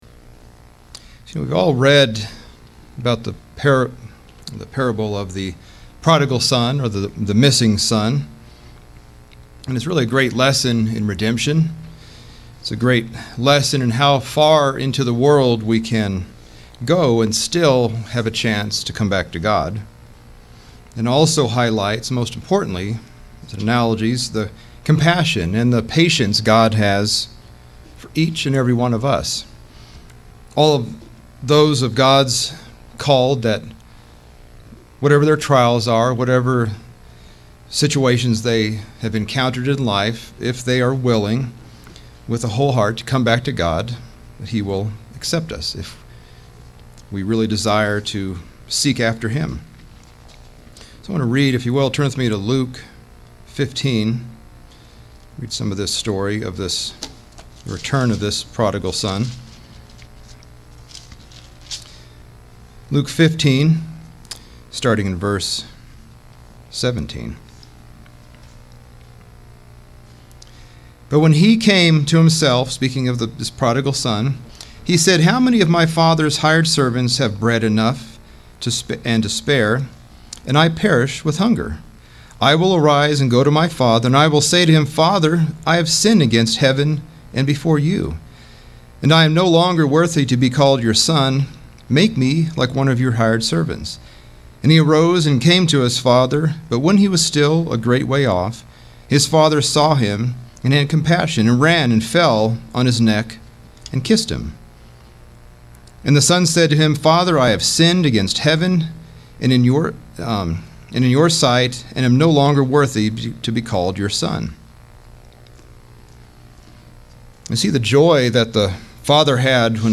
Sermons – Page 107 – Church of the Eternal God